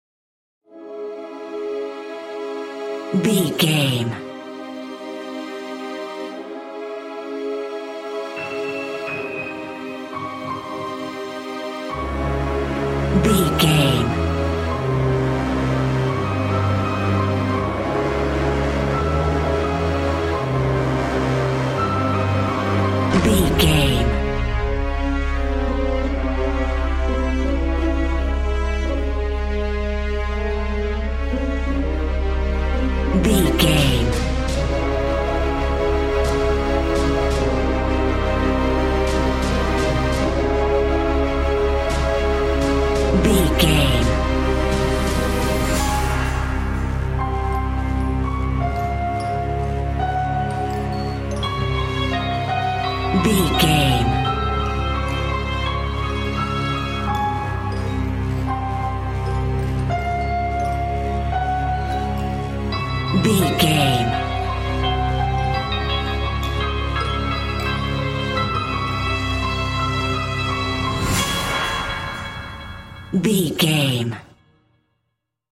Aeolian/Minor
C♯
Fast
disturbing
melancholy
foreboding
suspense
violin
strings
electric piano
synthesiser